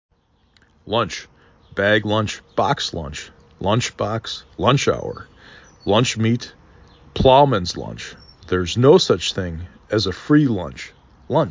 5 Letters, 1 Syllable
4 Phonemes
l uh n C